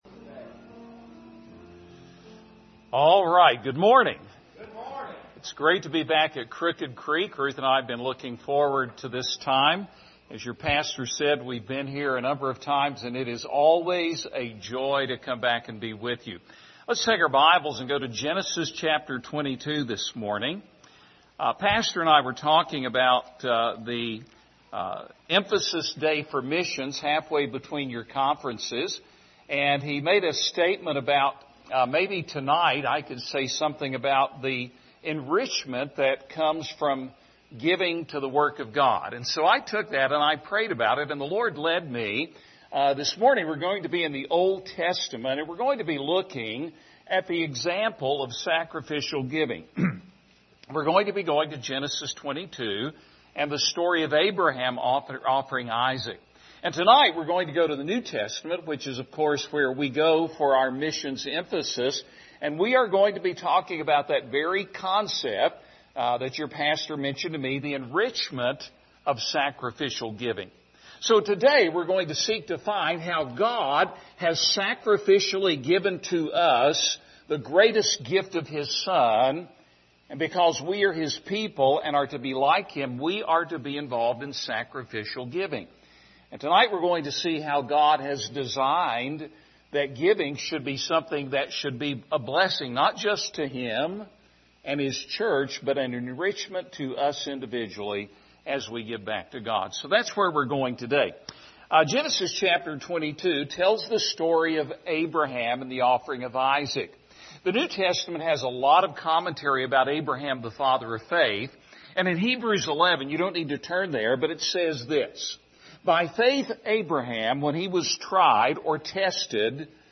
Passage: Genesis 22 Service Type: Sunday Morning Topics